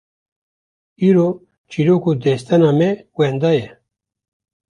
/t͡ʃiːˈɾoːk/